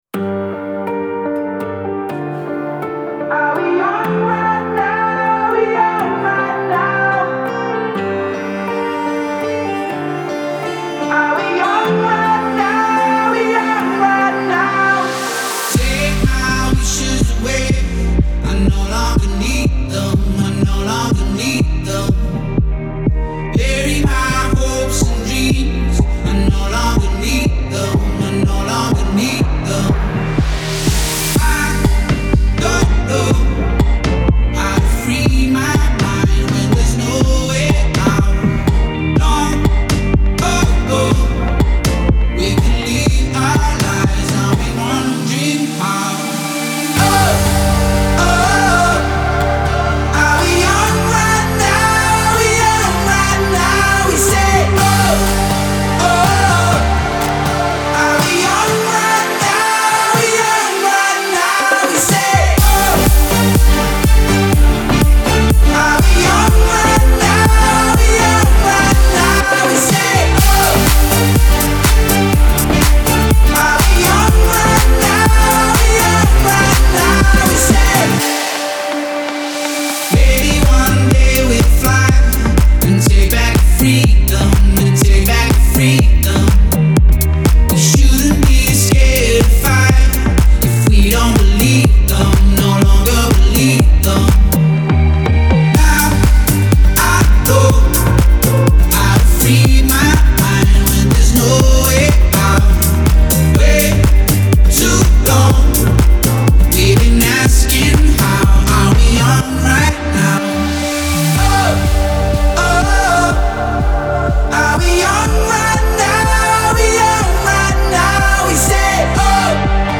Genre : Électronique, Dance